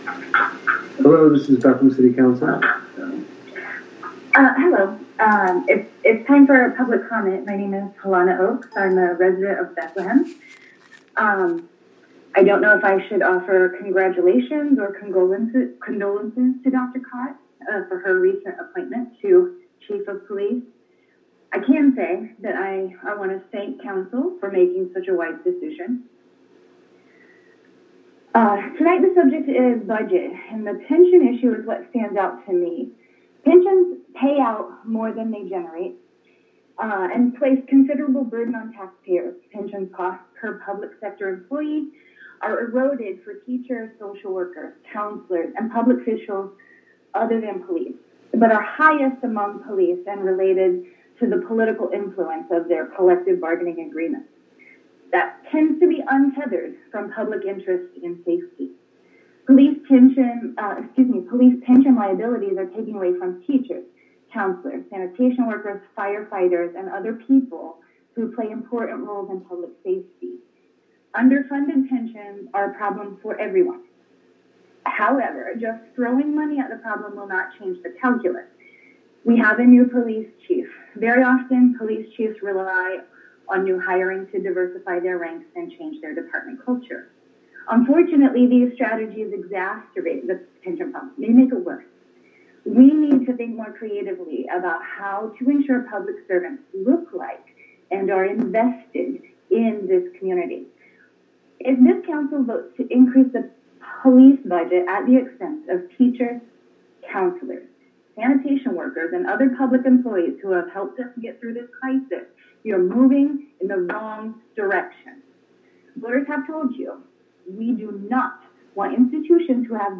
Budget Hearing November 9 video
public comment, begin min. 3:53:49